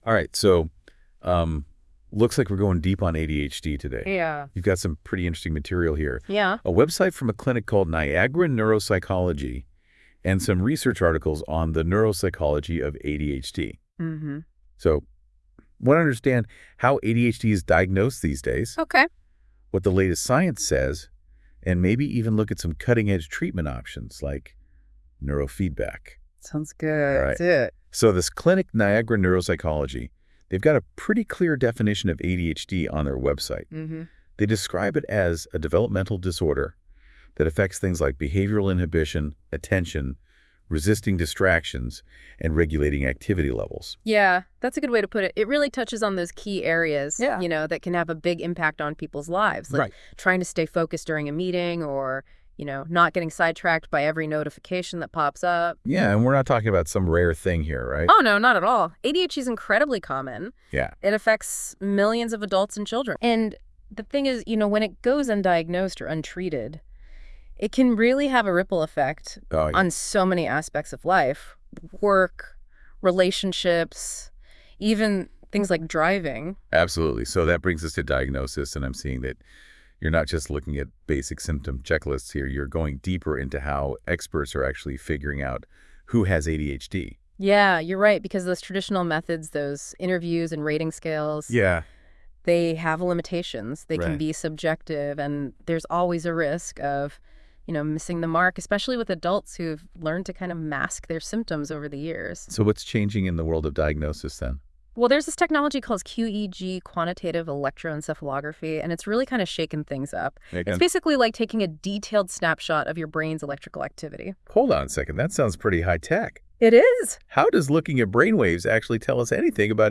CLICK TO HEAR AN AI GENERATED DISCUSSION OF THIS PAGE: